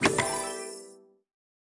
Media:ArcherQueen_baby_dep.wavMedia:ArcherQueen_base_dep.wavMedia:ArcherQueen_evo1_dep.wavMedia:ArcherQueen_evo2_dep.wav 部署音效 dep 在角色详情页面点击初级、经典、高手和顶尖形态选项卡触发的音效